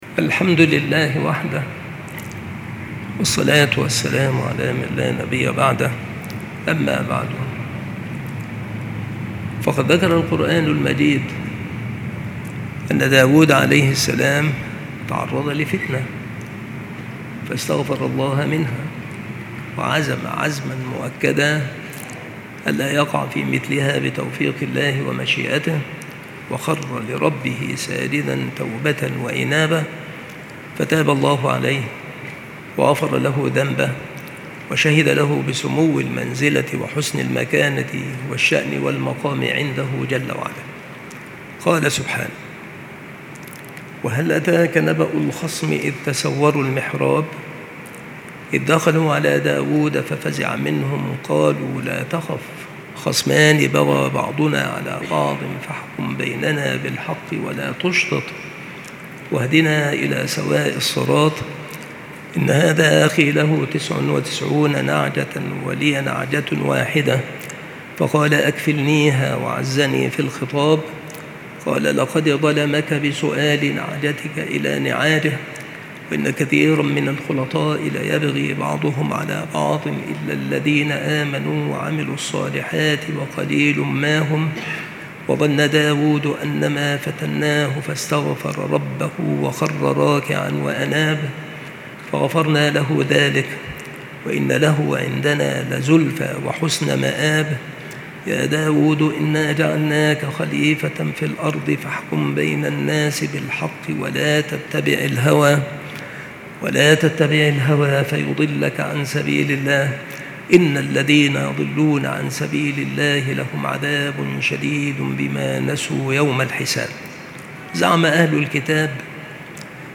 المحاضرة
مكان إلقاء هذه المحاضرة بالمسجد الشرقي - سبك الأحد - أشمون - محافظة المنوفية - مصر